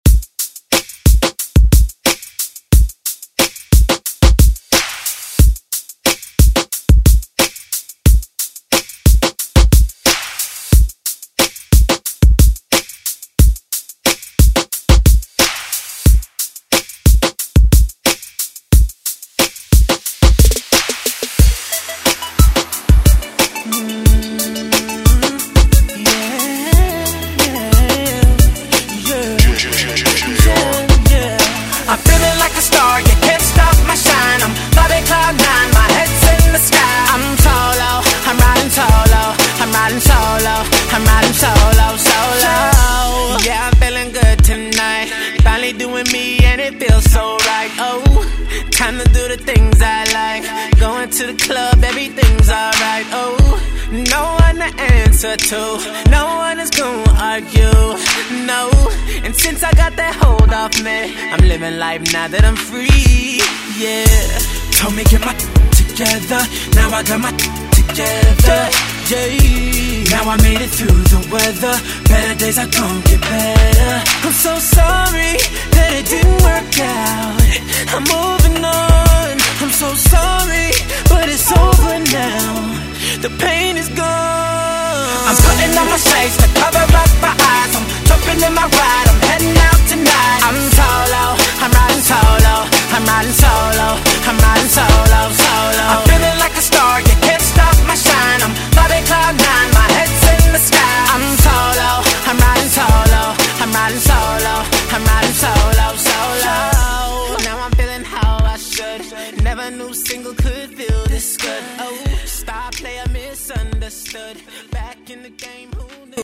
1993 Throwback Pop Rock Music Extended ReDrum Clean 70 bpm
Genre: 90's
BPM: 70